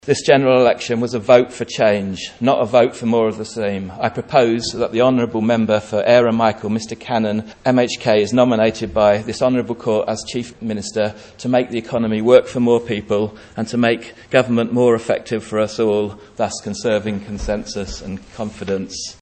It was a sentiment echoed by Douglas West representative Chris Thomas who proposed him for the job: